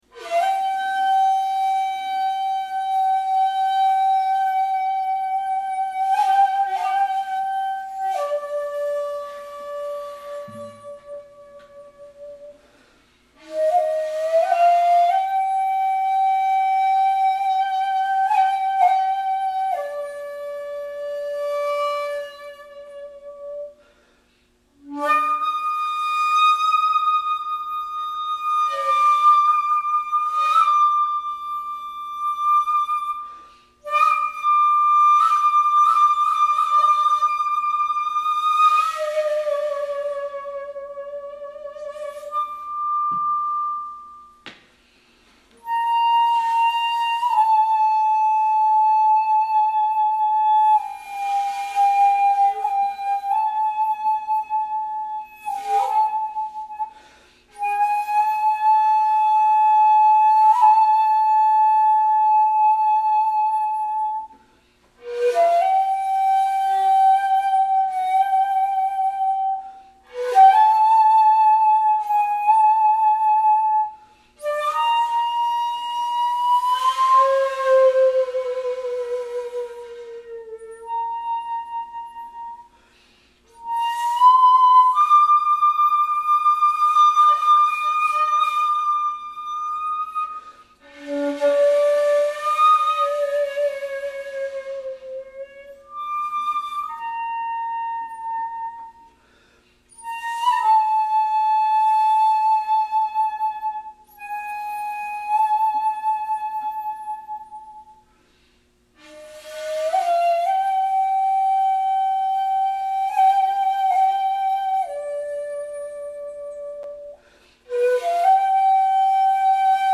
先日はじめてギターと下合わせ練習をしました。練習もなにもしていない段階で、尺八のミストーンもありますが、よろしければお聴きいただければ幸いです。